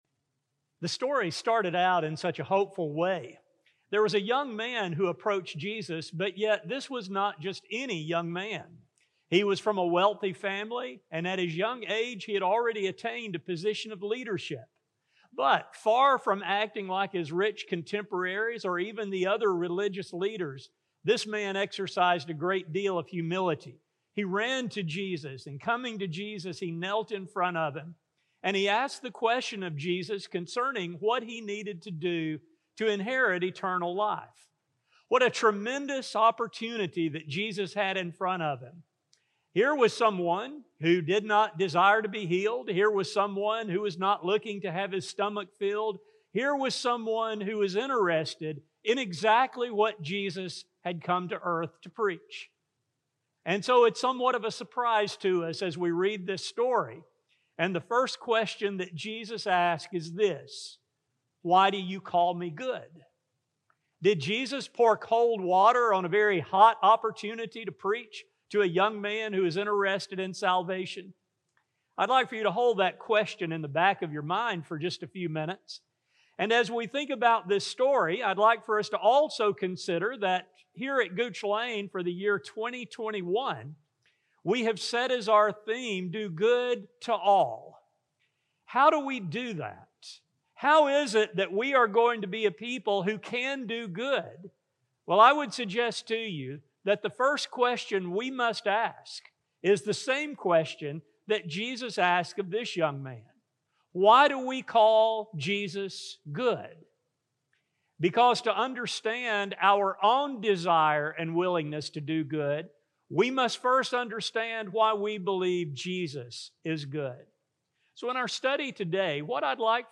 In this study, we explore how we come to answer this question and know Jesus as good. A sermon recording